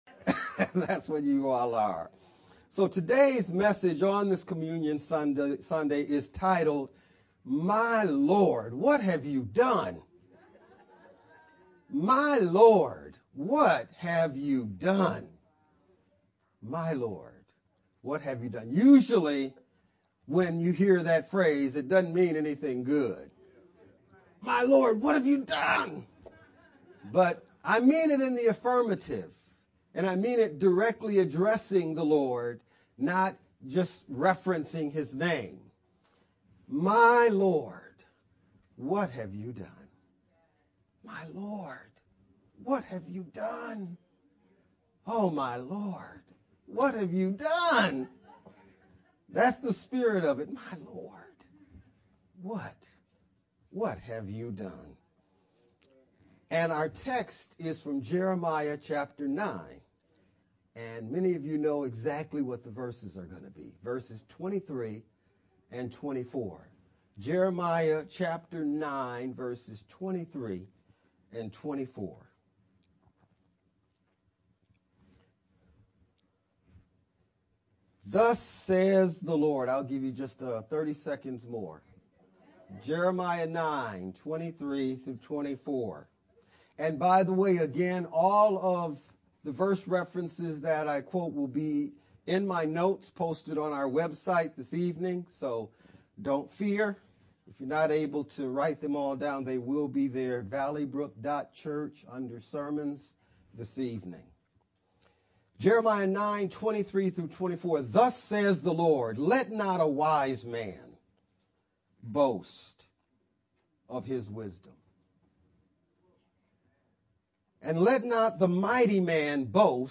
5-28-VBCC-Sermon-only-MP3-CD.mp3